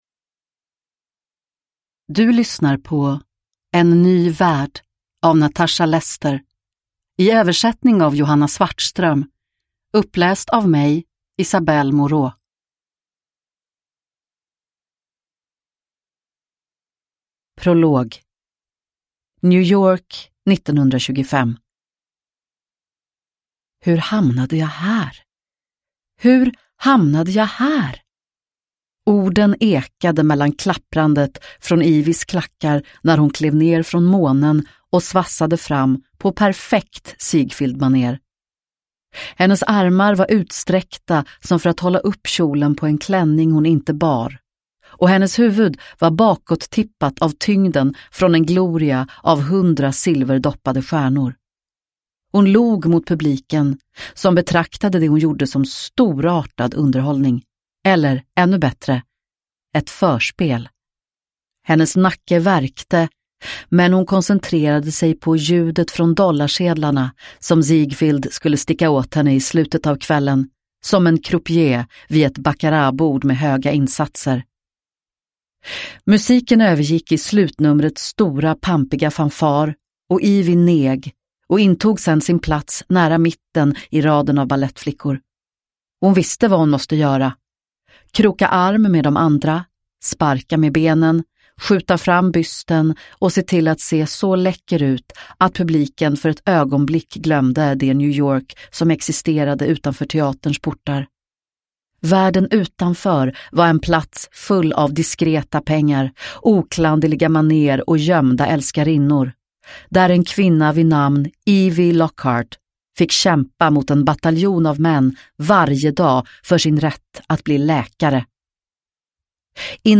En ny värld – Ljudbok – Laddas ner